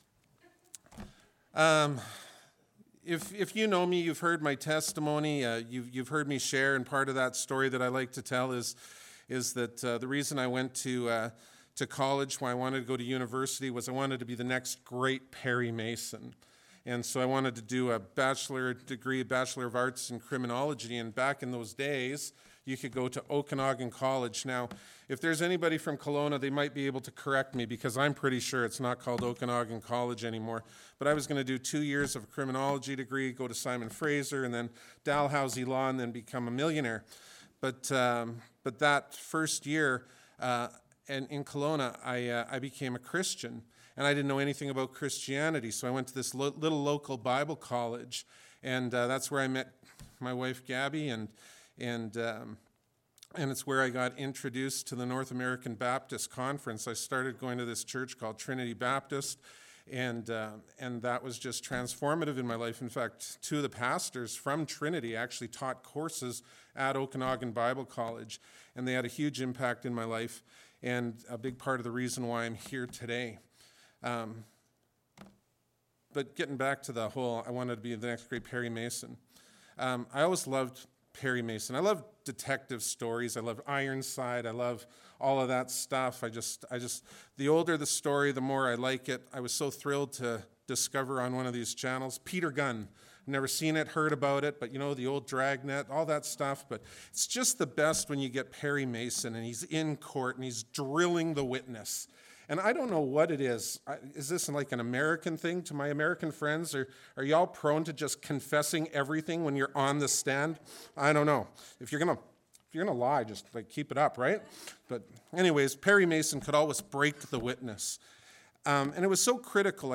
Bible Text: John 1:6-9 | Preacher